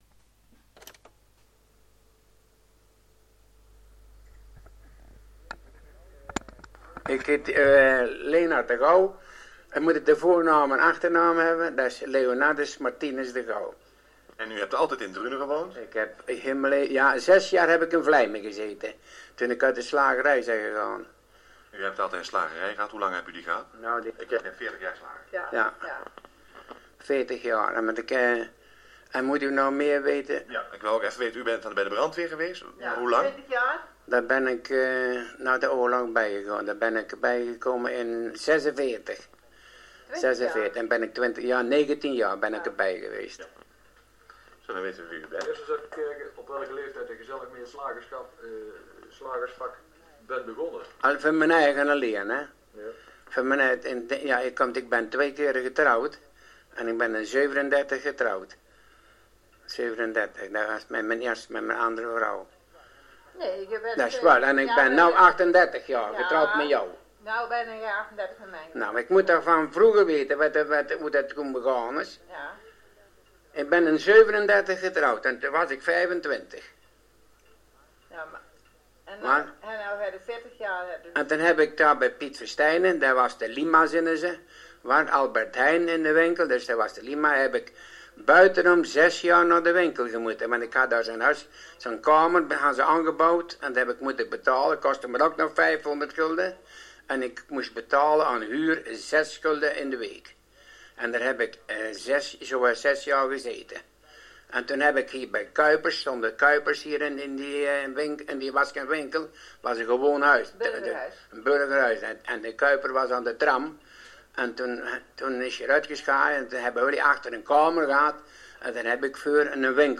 Door de jaren heen zijn er verschillende opnames gemaakt van gesprekken met sprekers van de Langstraatdialecten.